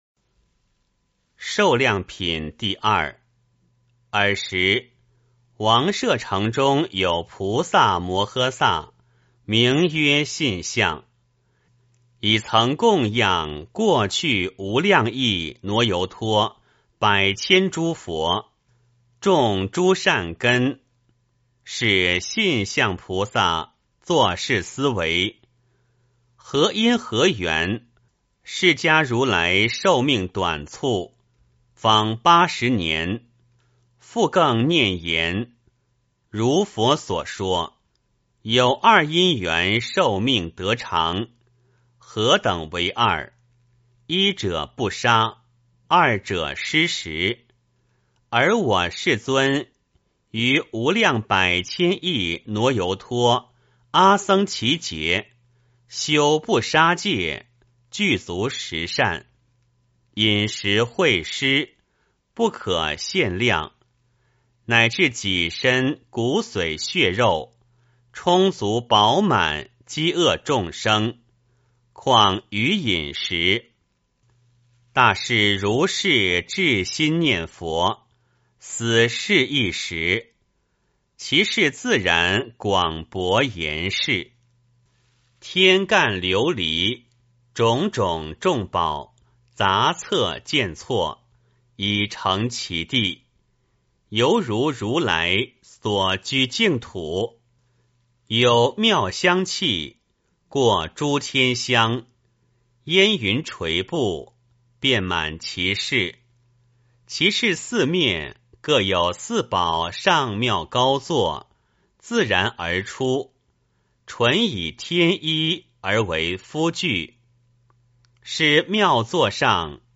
金光明经-02-念诵 诵经 金光明经-02-念诵--未知 点我： 标签: 佛音 诵经 佛教音乐 返回列表 上一篇： 金光明经-01-念诵 下一篇： 佛说疗痔病经（念诵） 相关文章 晚课-佛说阿弥陀经 往生咒 赞佛偈--慧律法师率众 晚课-佛说阿弥陀经 往生咒 赞佛偈--慧律法师率众...